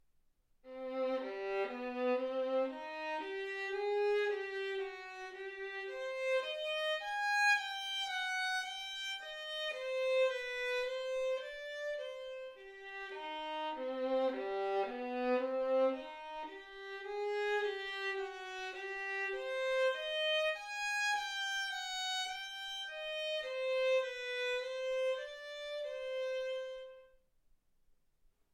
Hegedű etűdök Kategóriák Klasszikus zene Felvétel hossza 00:29 Felvétel dátuma 2025. december 8.